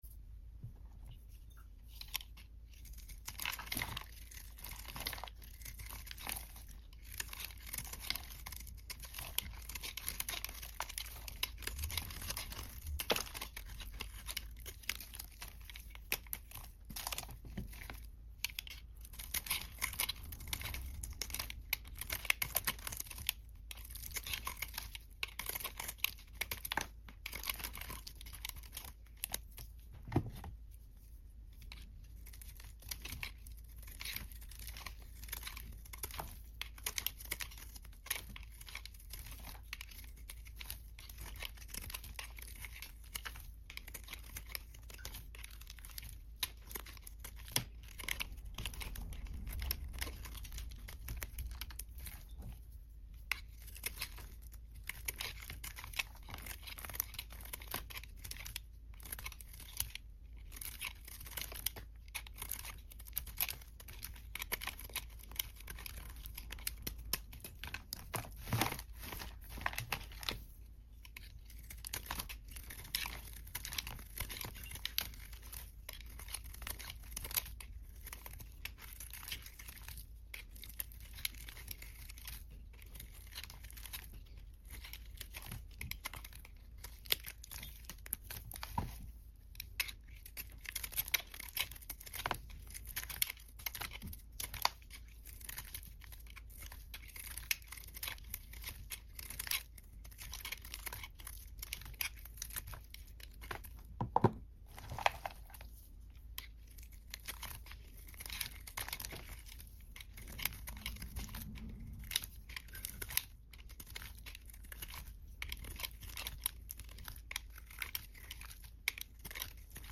Red básquet Soap white cutting#asmrsoap2x